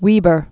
(wēbər)